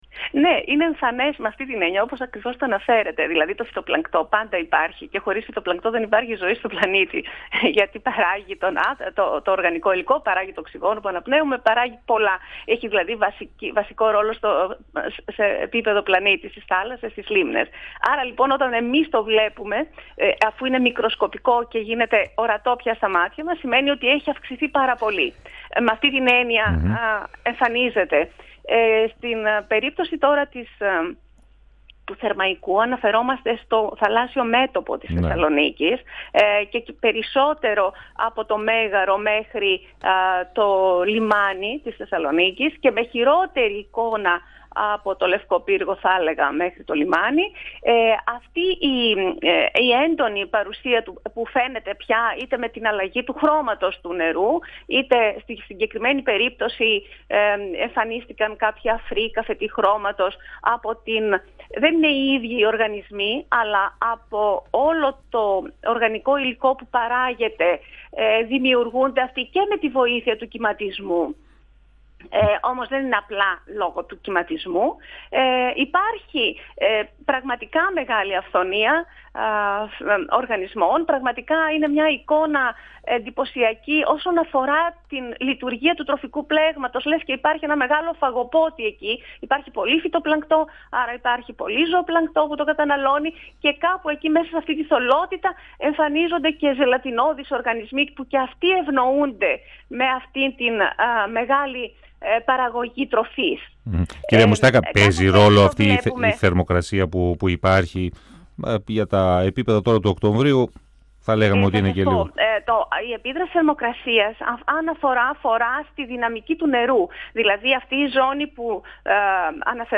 στον 102FM του Ρ.Σ.Μ. της ΕΡΤ3